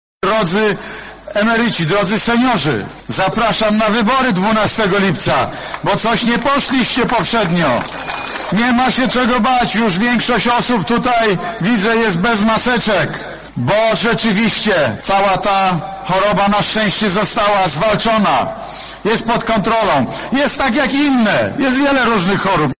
Podczas zgromadzeń w podlubelskich powiatach Prezes Rady Ministrów nawoływał do oddawania głosów na Andrzeja Dudę i dziękował za dotychczasowe wyniki. Podkreślał, że pójście na wybory w obecnej sytuacji jest bezpieczne i nie zagraża zdrowiu:
M. Morawiecki